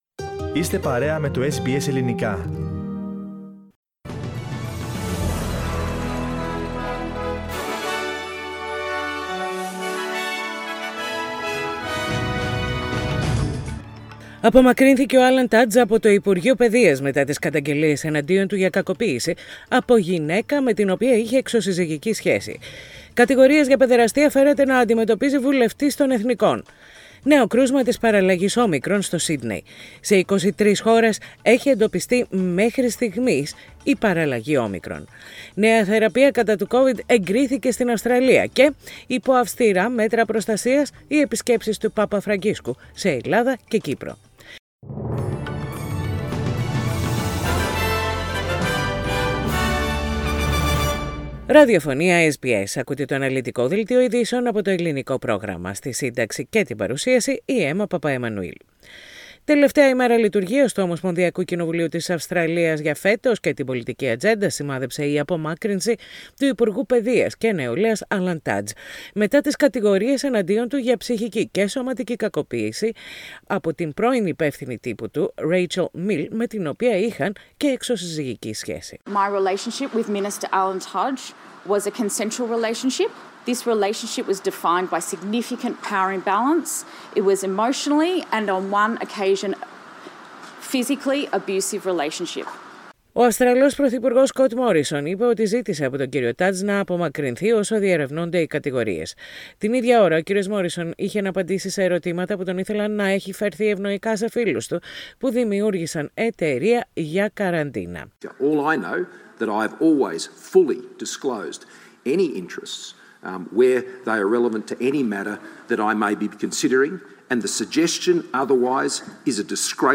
The detailed news bulletin of the day, with news from Australia, Greece, Cyprus and the rest of the world.